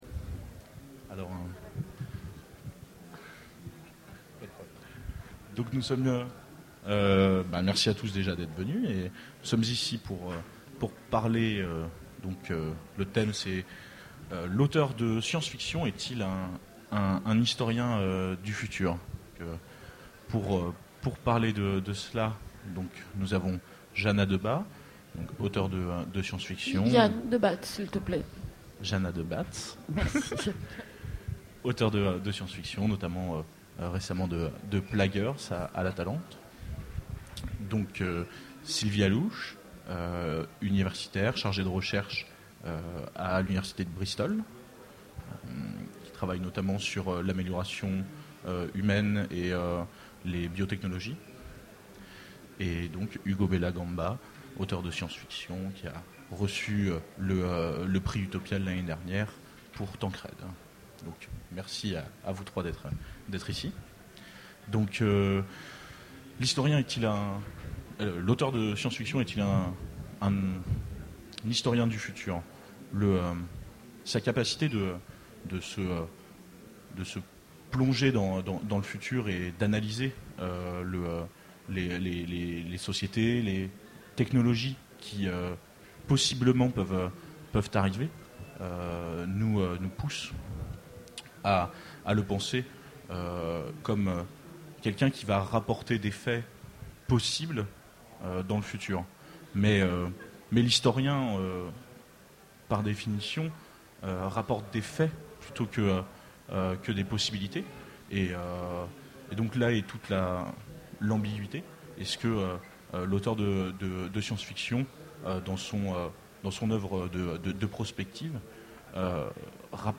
Utopiales 2011 : Conférence L'auteur de SF est-il un historien du futur ?